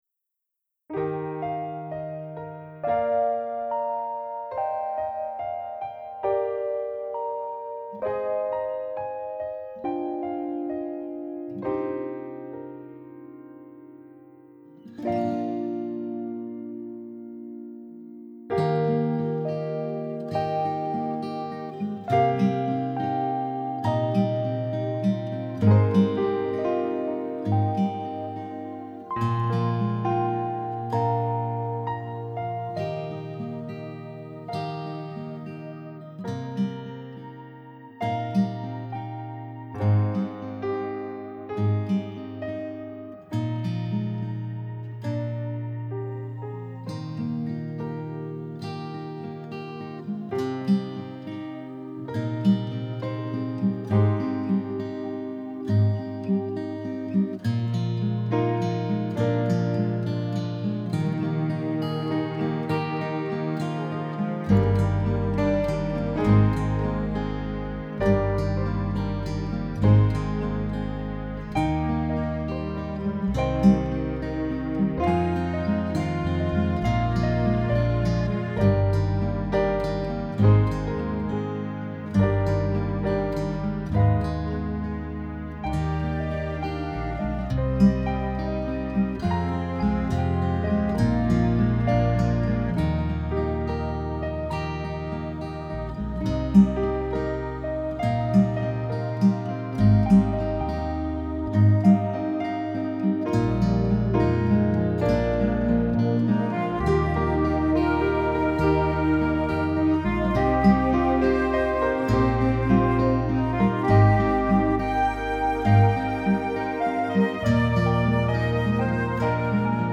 The first four links below are versions of my new arrangement (with and without vocals and/or instrumentation).
I do still cry and my voice reflects that when I sing my song.